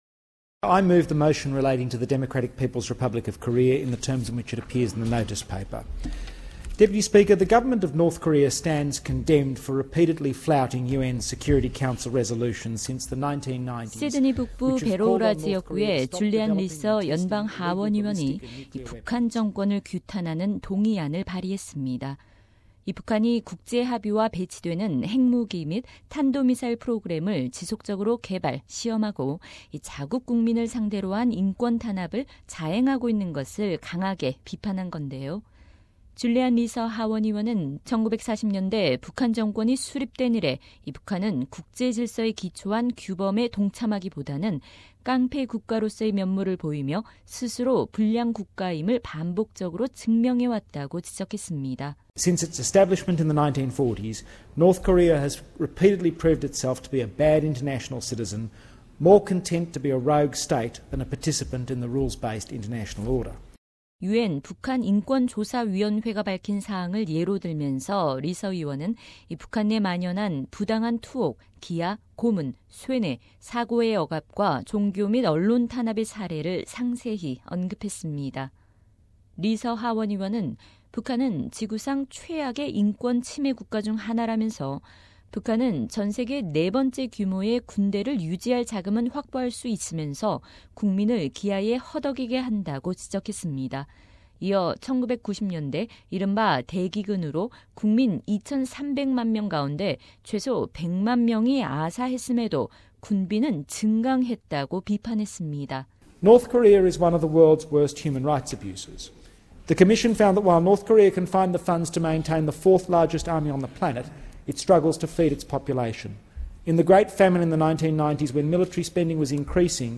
SBS 라디오 한국어 프로그램은 줄리안 리서 연방 하원의원과의 인터뷰를 통해 동의안에 대한 좀 더 자세한 얘기를 들어봤습니다.